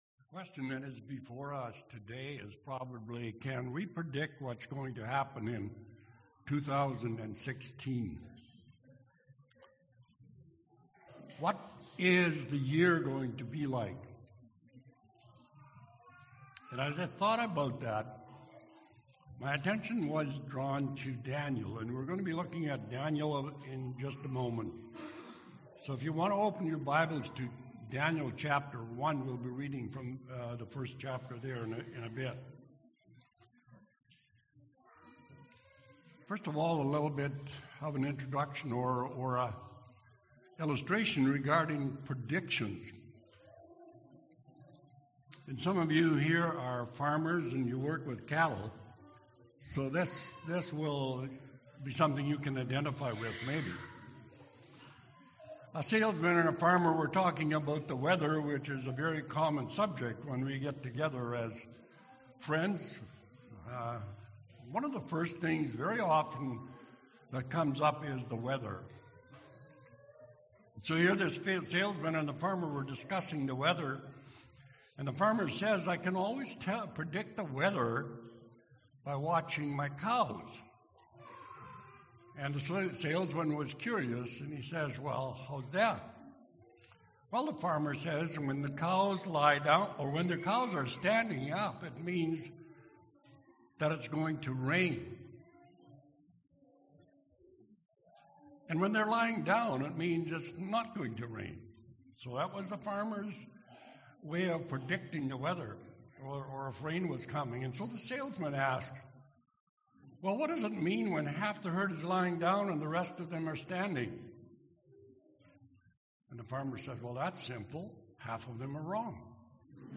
Jan. 3, 2016 – Sermon